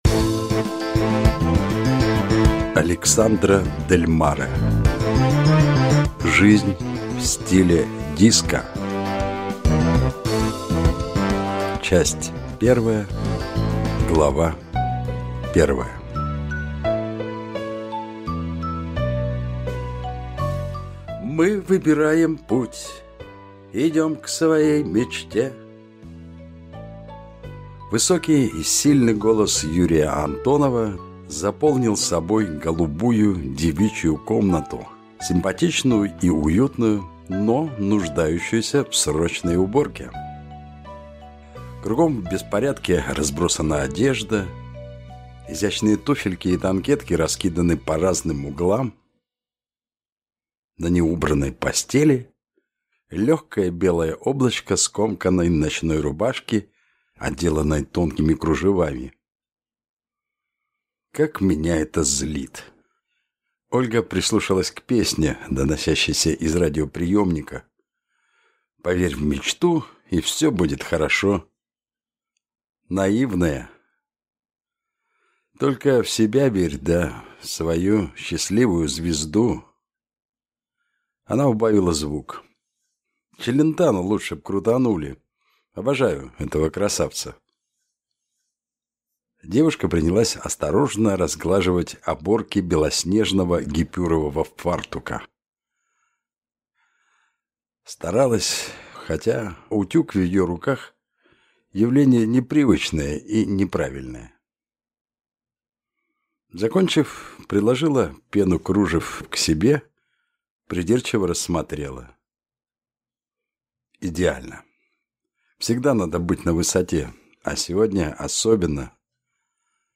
Аудиокнига Жизнь в стиле диско | Библиотека аудиокниг